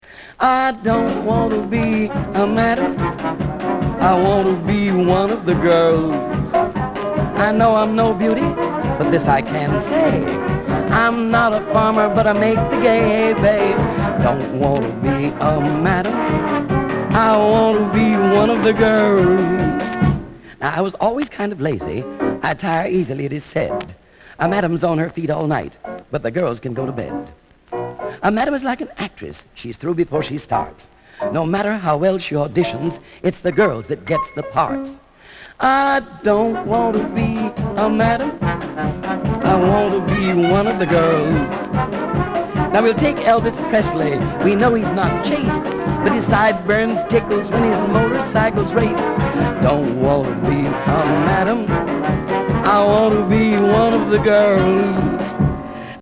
were recorded in New Orleans